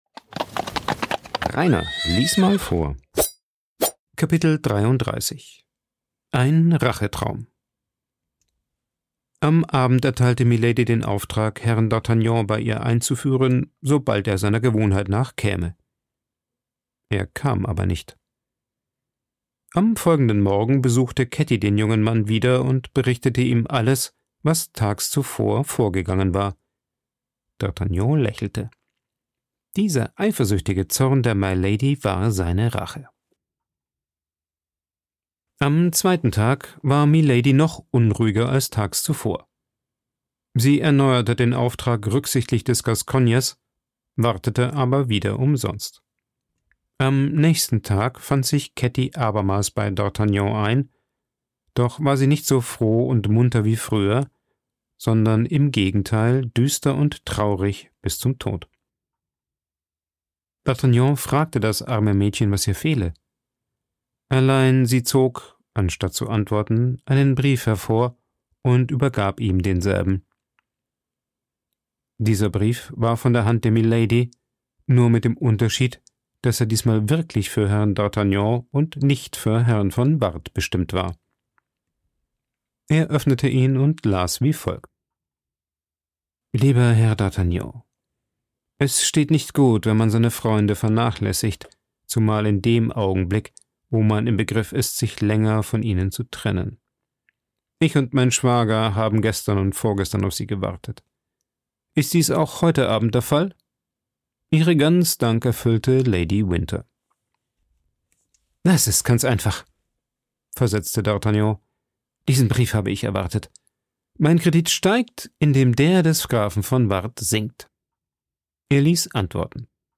Vorgelesen
aufgenommen und bearbeitet im Coworking Space Rayaworx, Santanyí, Mallorca.